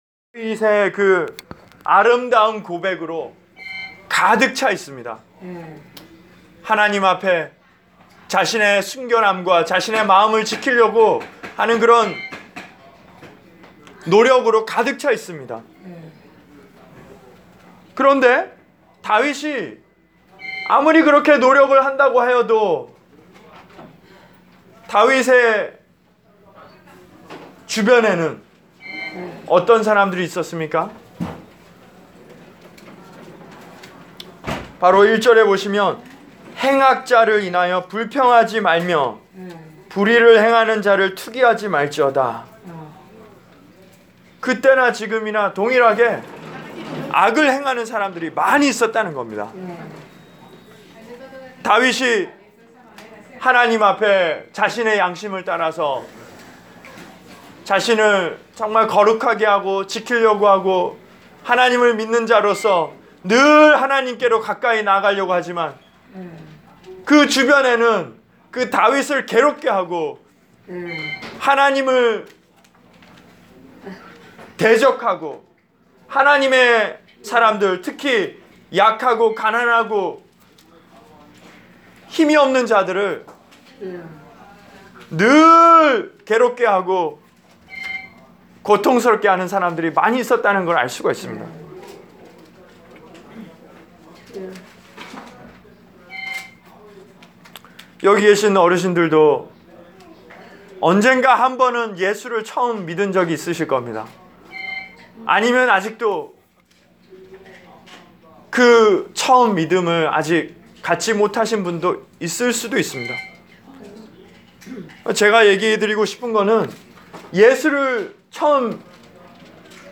Preached for: Hudson View and Rehab Center, North Bergen, N.J.